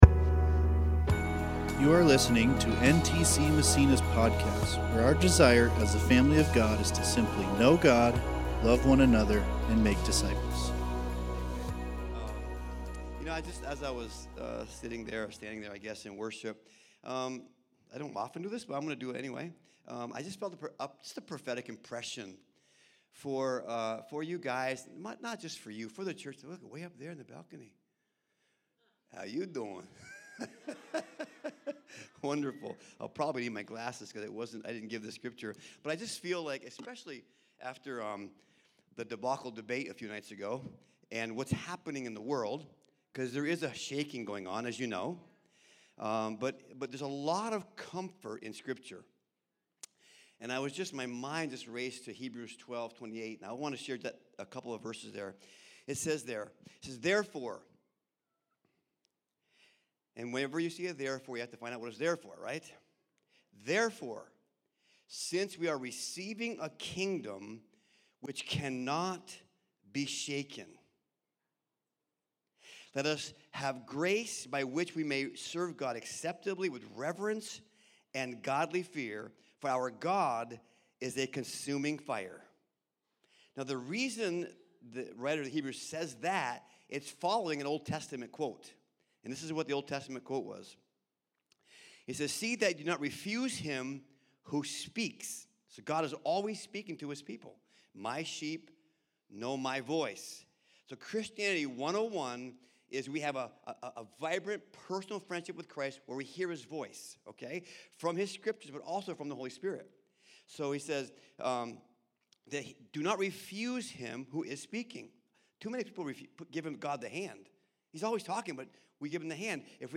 2024 God’s Very Own Family Preacher